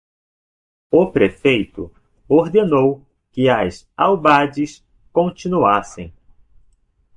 /pɾeˈfej.tu/